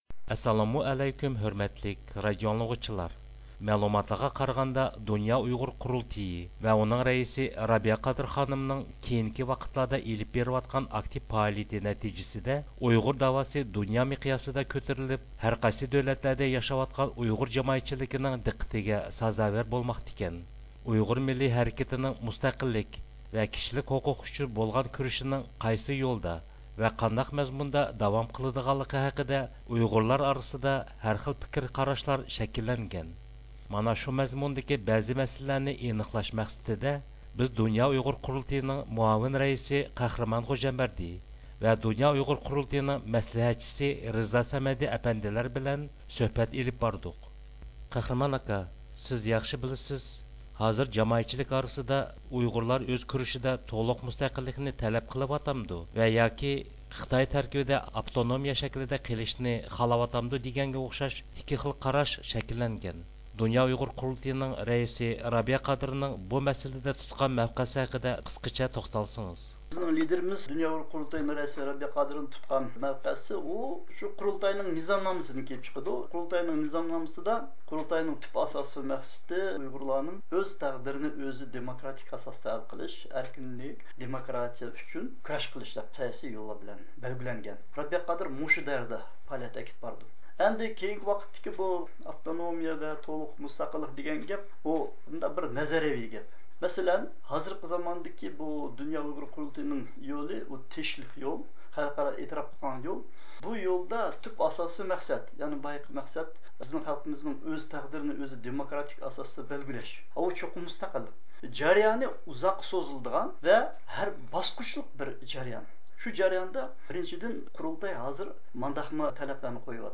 سۆھبەت ئېلىپ باردۇق.